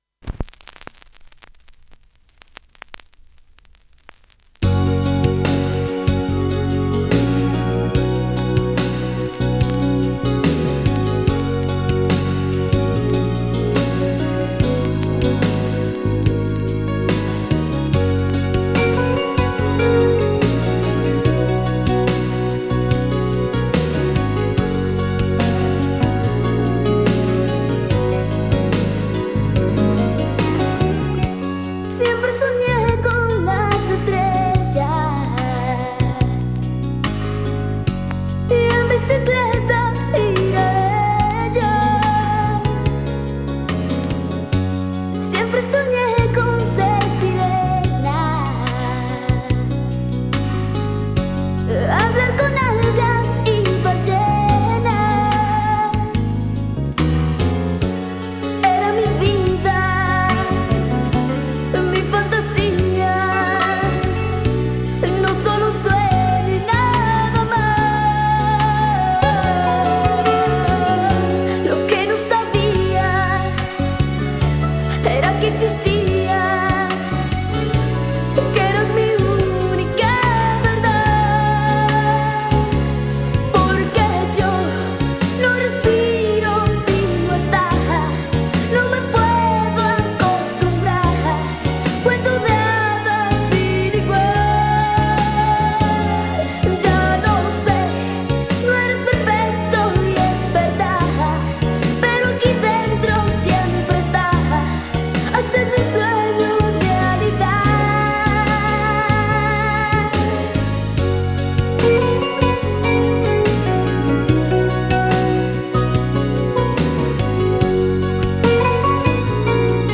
Latin pop